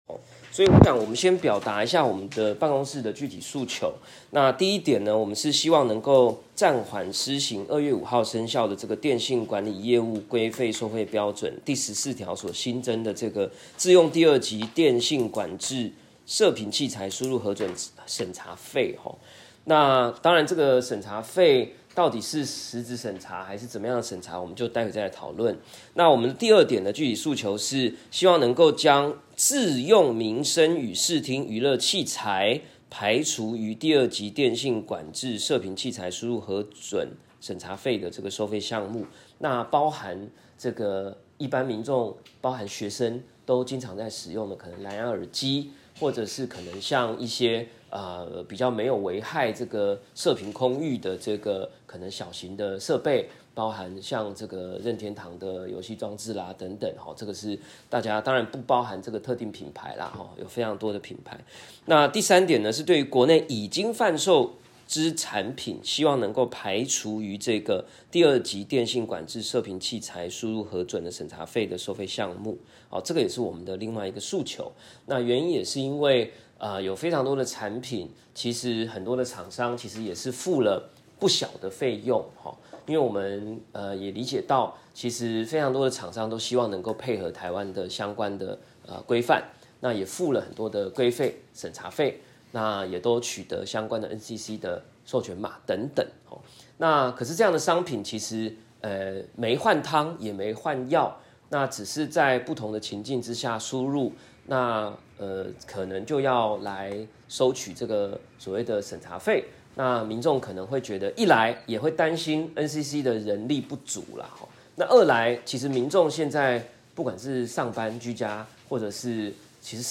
本會議取得出席者同意，錄音公開逐字稿 - 為 Whisper 轉錄，有錯誤歡迎留言分享。